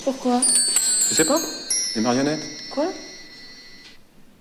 最妙的是[A:35:45; 35:29]法国维罗妮卡端着钟琴被人搭讪，最后谈到木偶艺人，钟琴看似随意地乱响了一阵